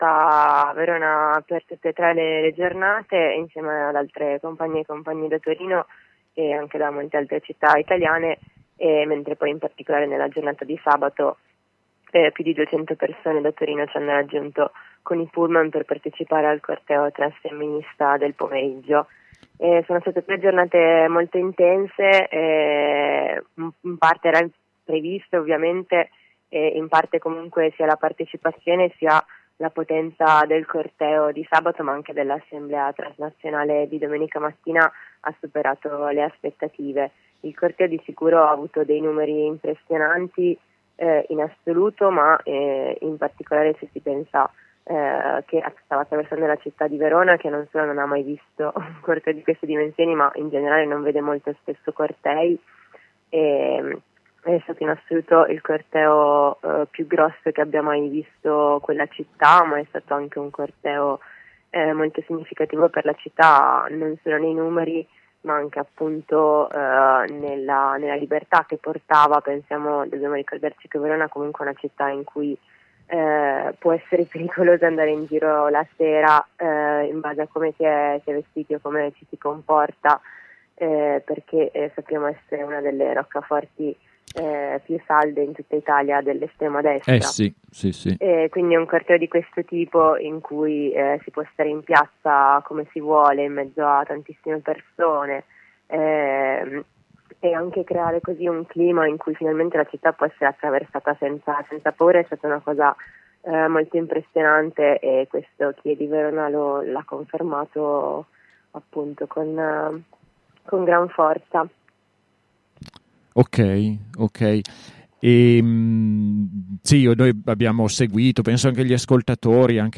Intervista
Ascolta la diretta: